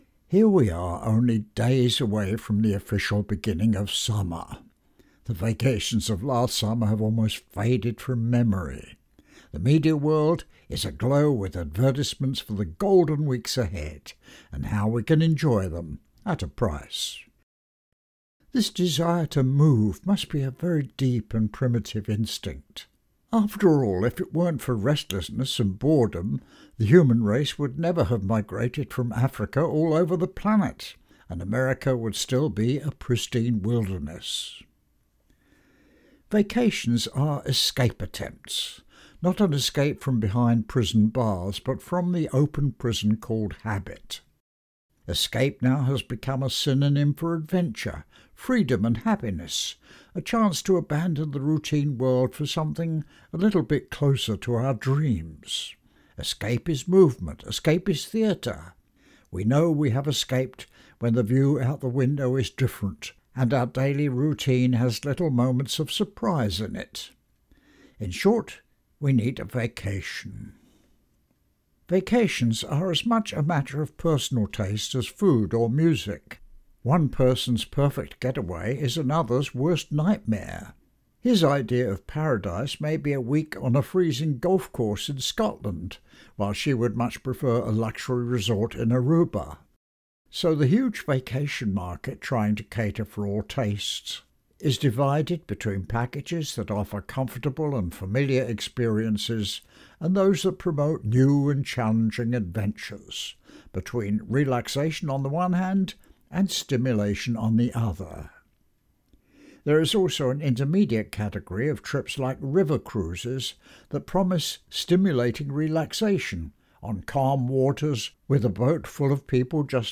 … continue reading 22 episodes # Society # Conversations # WSHU # WSHU Public Radio